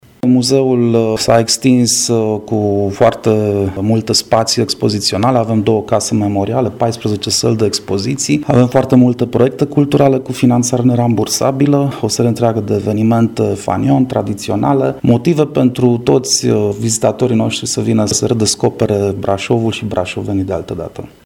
Muzeul „Casa Mureșenilor” Brașov organizează ediţia a XVII-a a Sesiunii de Comunicări ştiinţifice „Ţara Bârsei”.